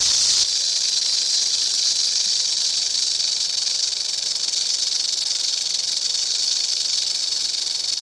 RattleSnake.ogg